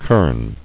[kern]